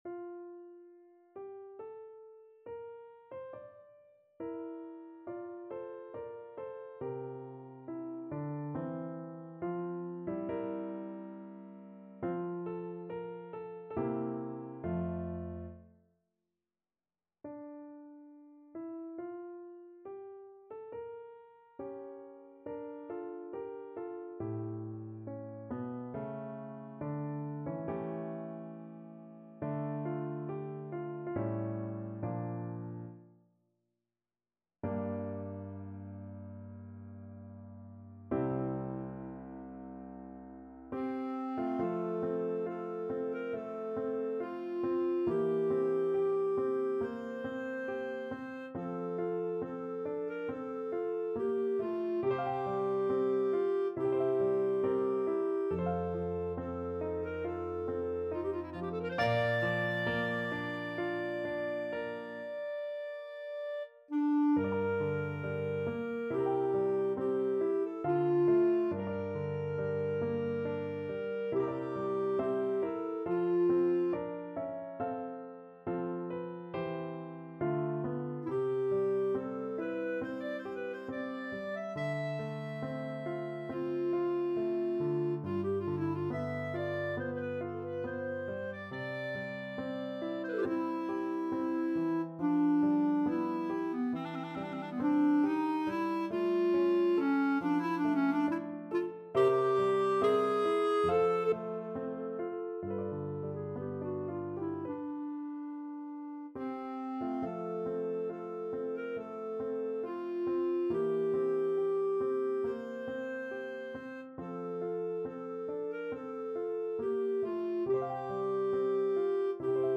4/4 (View more 4/4 Music)
Larghetto (=80) =69
F major (Sounding Pitch) G major (Clarinet in Bb) (View more F major Music for Clarinet )
Clarinet  (View more Intermediate Clarinet Music)
Classical (View more Classical Clarinet Music)